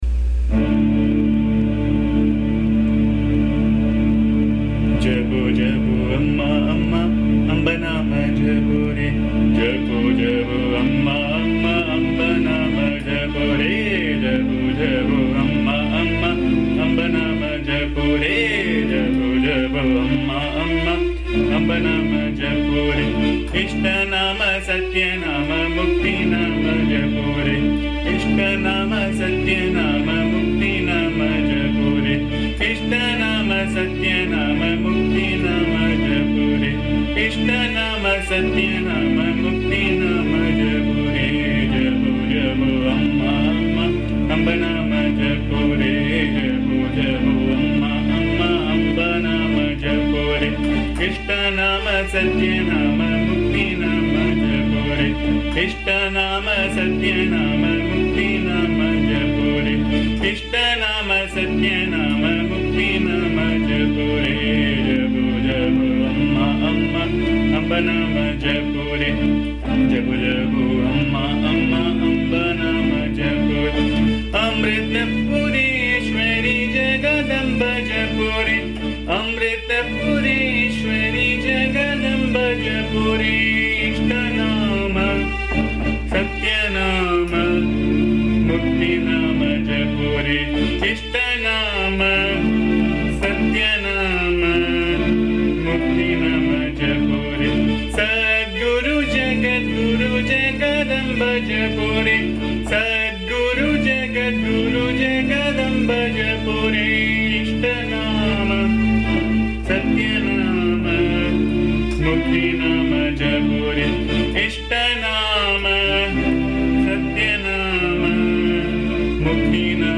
AMMA's bhajan song